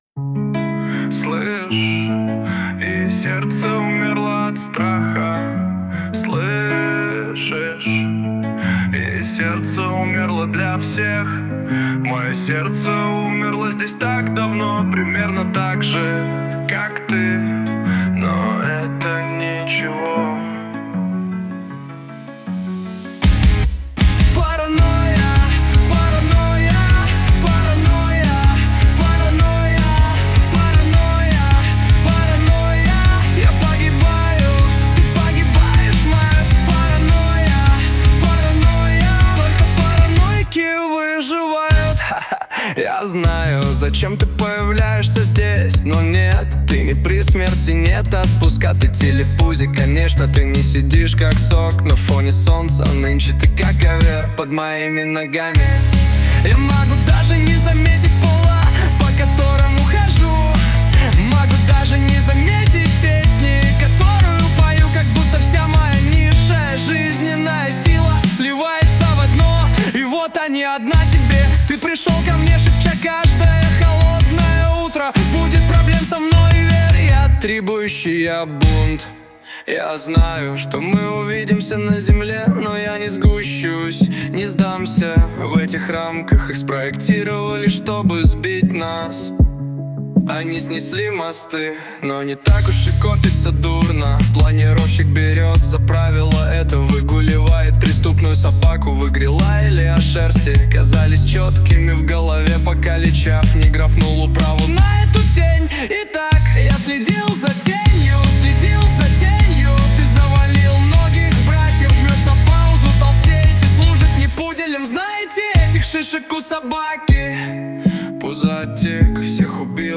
RUS, Rap | 17.03.2025 16:29